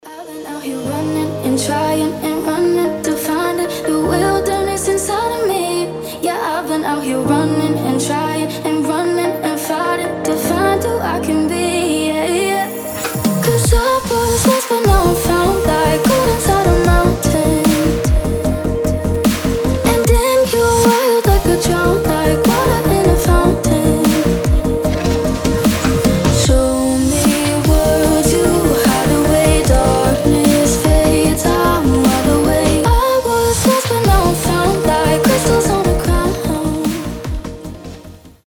мелодичные
спокойные
красивый женский голос
future bass